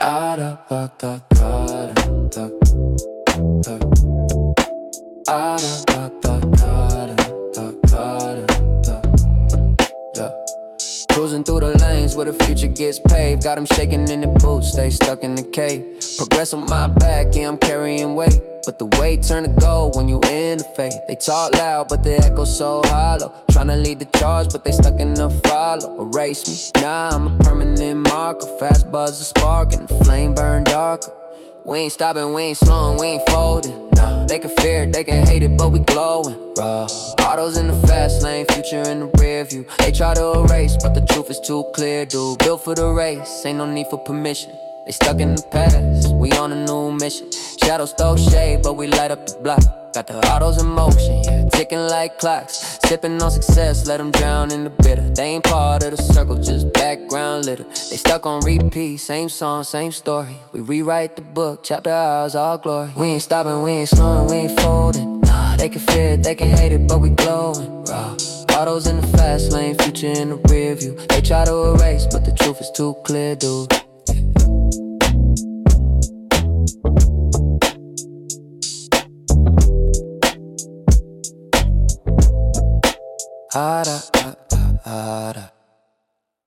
rap 17 Dec 2025